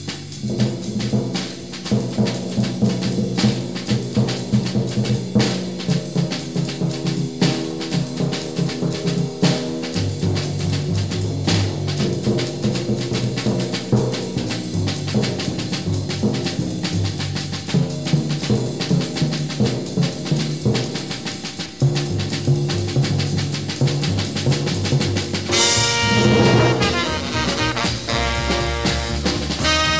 This sounds too clean to be shellac? great groove still.
Right, this was vinyl.